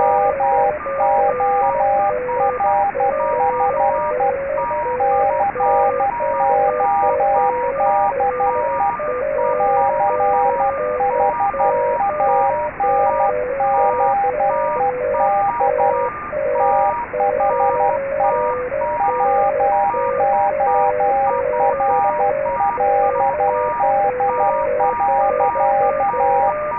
Omdat we alleen lage tonen tot zo'n 1500 Hz gebruiken, is de sample rate van de WAV files laag gehouden, 4800 samples/sec.
Morse en stoorsignalen